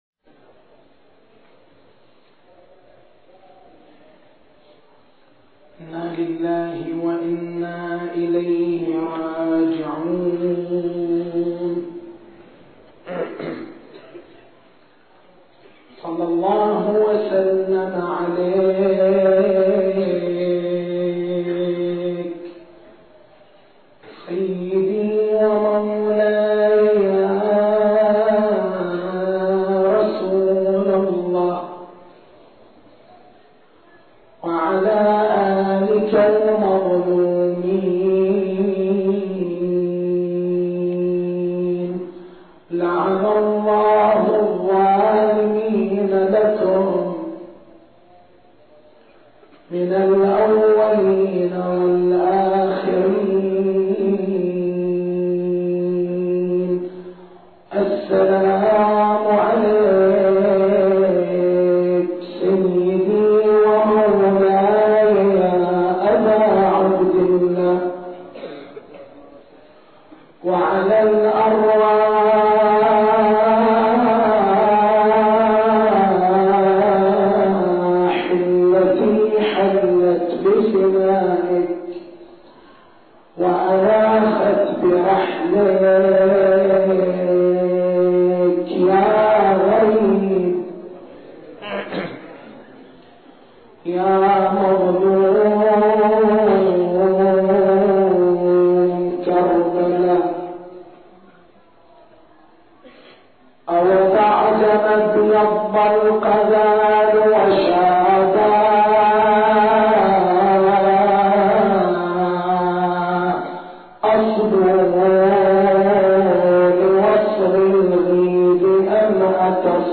تاريخ المحاضرة
محرم الحرام 1425